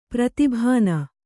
♪ prati bhāna